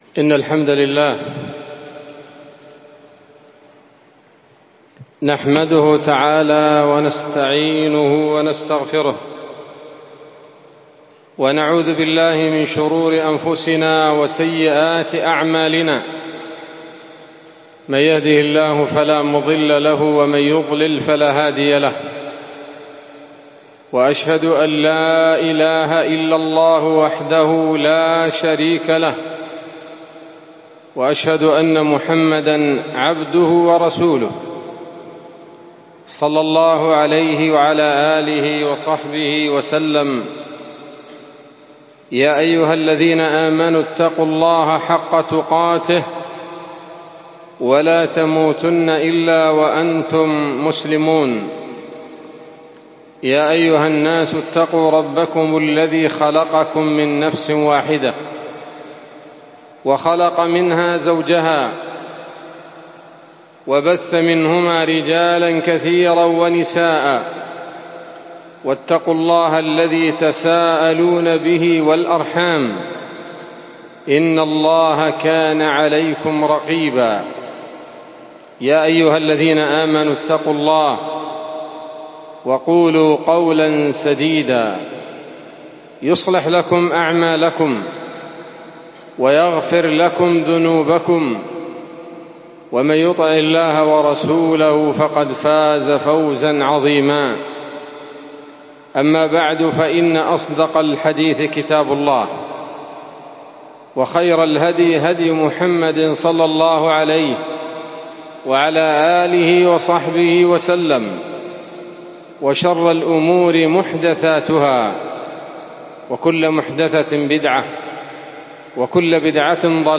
محاضرة قيمة بعنوان: (( ‌غلاء الأسعار في الإٍسلام )) ليلة السبت 15 صفر 1447هـ، بمسجد حافون - المعلا - عدن